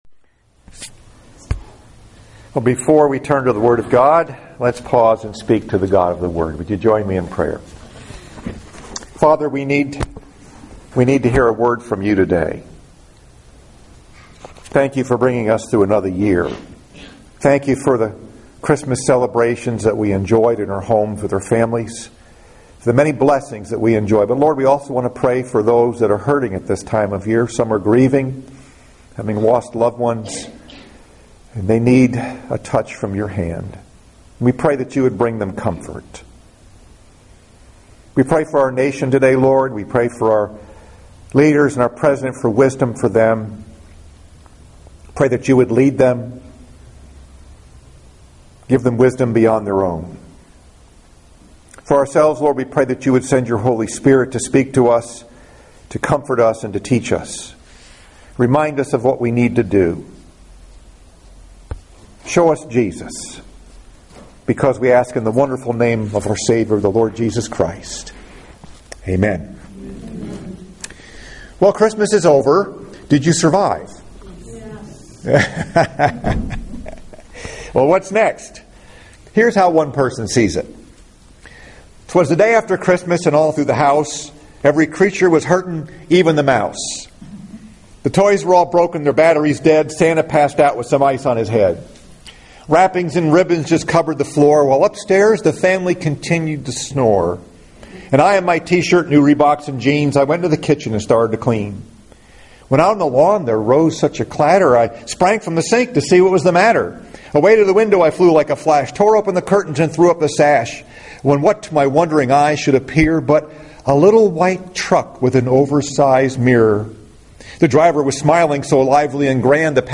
Message: “Christmas Revisited” Scripture: Philipians 2:5-11 FIRST SUNDAY AFTER CHRISTMAS